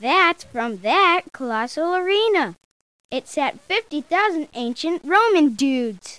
Kid voice clip in Mario is Missing! CD-ROM Deluxe
MIMDX_Kid_Gladiator's_spear.oga.mp3